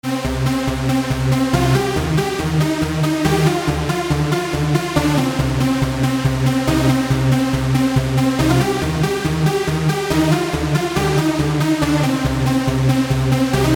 阿尔伯特钟声
描述：阿尔伯特纪念钟楼在贝尔法斯特，北爱尔兰，两次鸣响。这是从塔内记录的，因此交通噪音最小化。
标签： 艾伯特时钟 时钟塔 铃贝尔法斯特